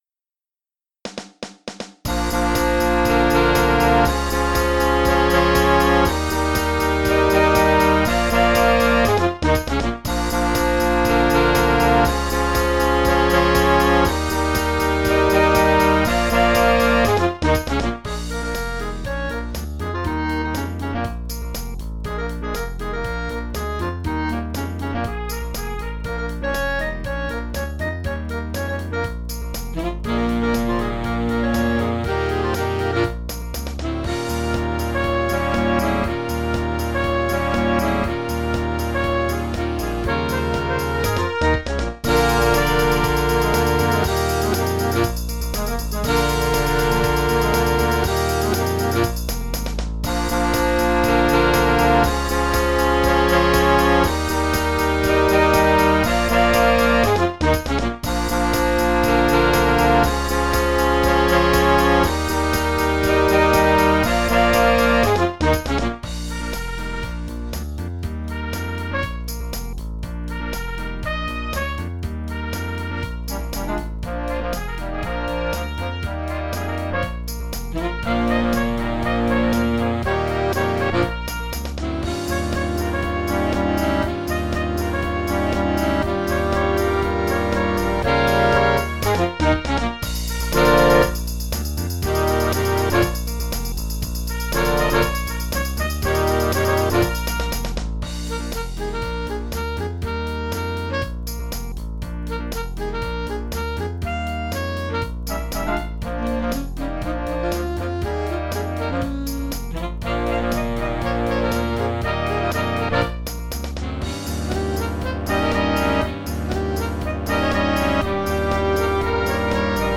Genre: Jazz
Orchestre d'Harmonie